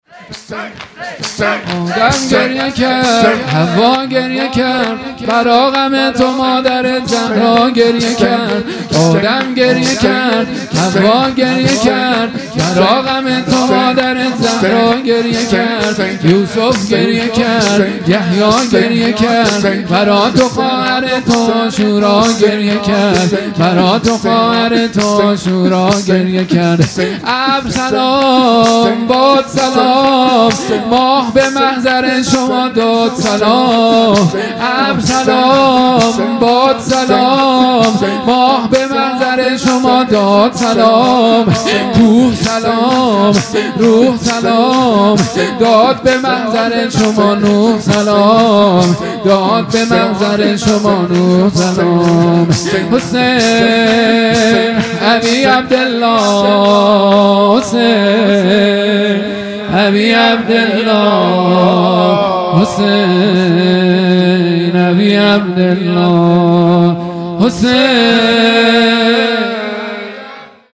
0 0 شور | آدم گریه کرد هوا گریه کرد مداح